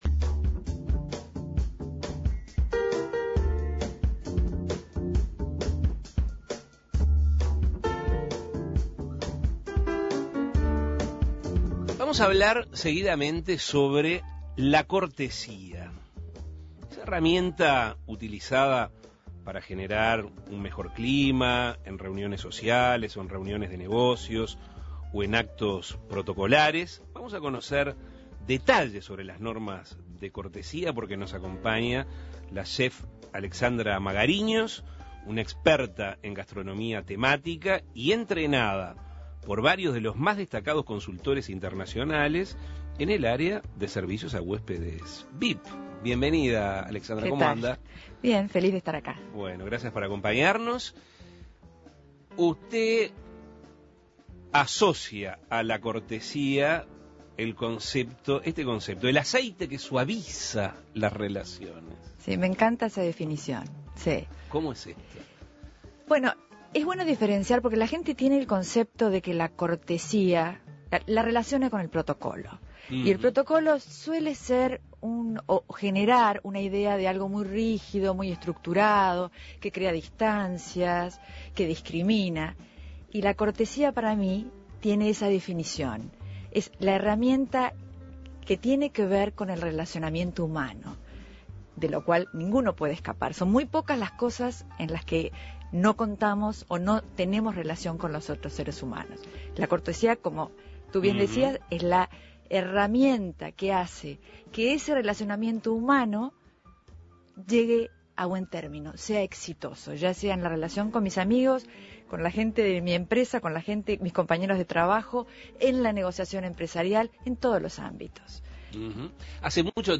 También, sobre desde cuándo se utilizan normas de cortesía para ese fin, en qué partes del mundo se aplican, cómo se debe proceder cuando el evento es en un lugar neutral, qué debe hacer el anfitrión y qué se espera del invitado o agasajado, entre otras cosas. Escuche la entrevista.